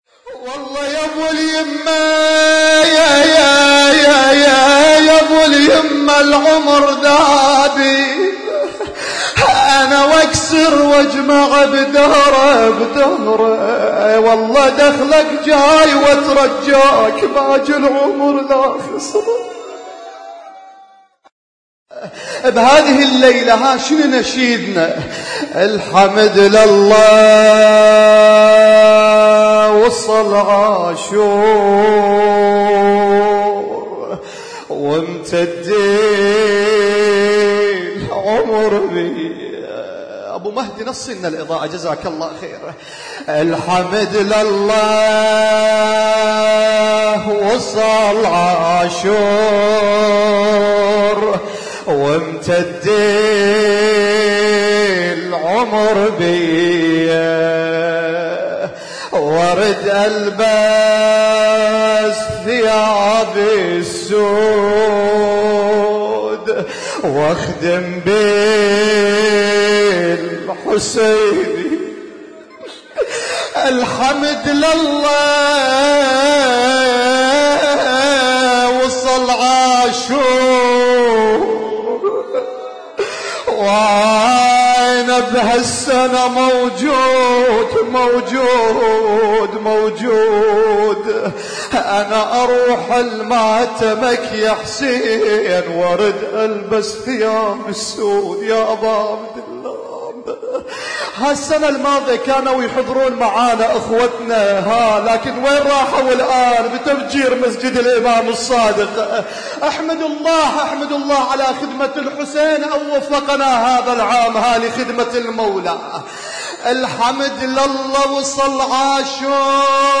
اسم التصنيف: المـكتبة الصــوتيه >> الصوتيات المتنوعة >> النواعي